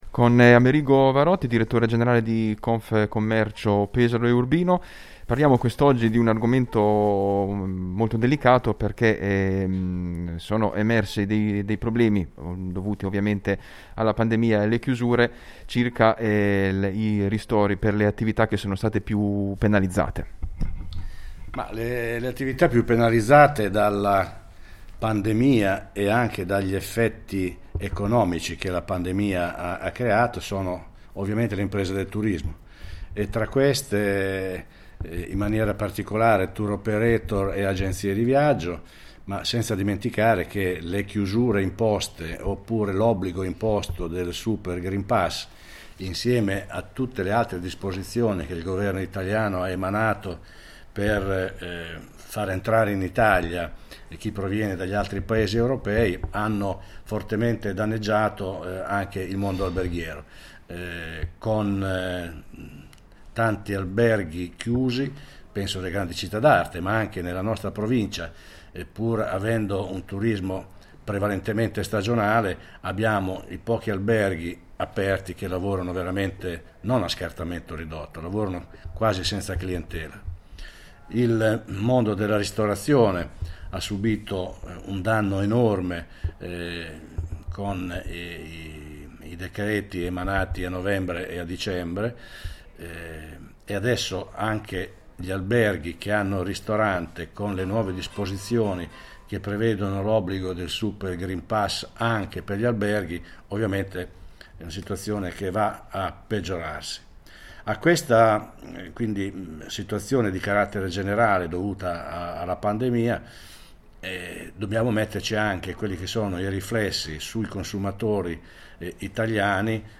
interviene ai nostri microfoni sull’emergenza turismo. Ecco le proposte dell’Associazione di Categoria, per fronteggiare l’emergenza che ancora una volta ha duramente colpito il settore.